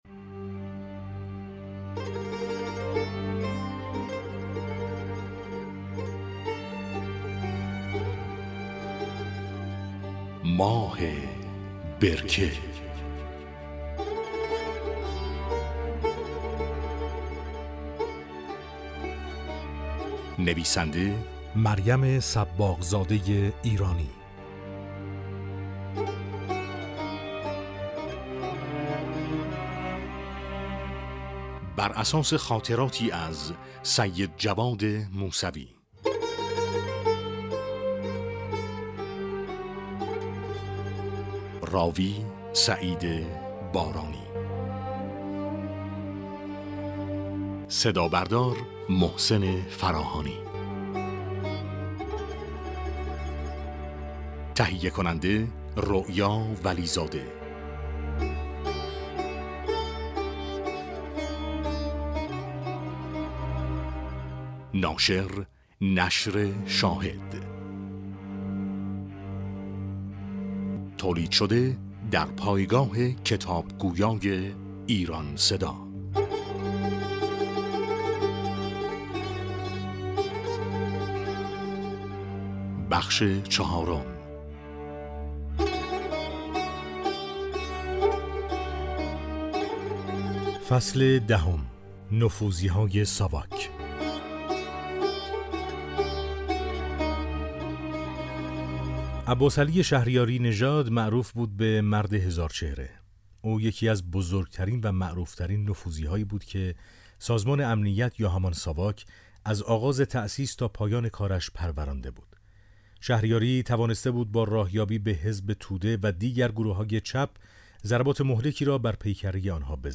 نوید شاهد - امکان دانلود رایگان کتاب صوتی «ماه برکه» که از آثار «نشر شاهد» است، در پایگاه خبری نوید شاهد فراهم شد.